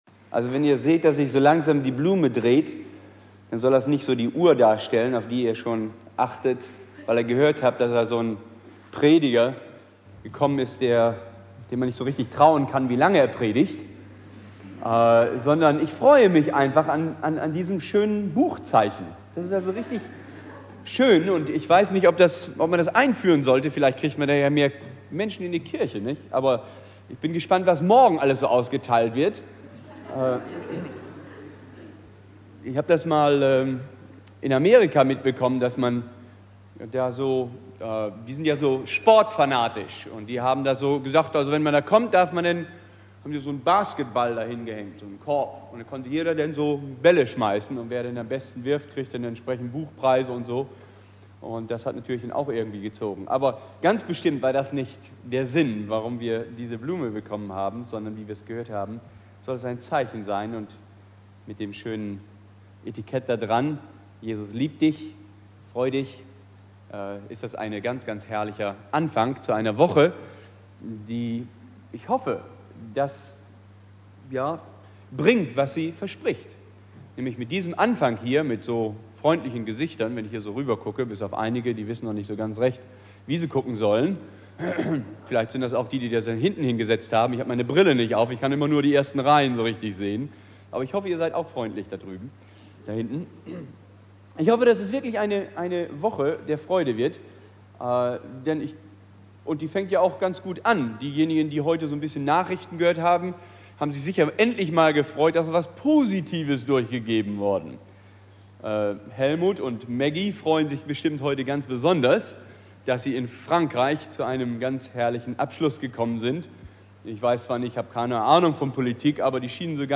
Predigt
erster Abend der Evangelisation Bibeltext: Jeremia 31,3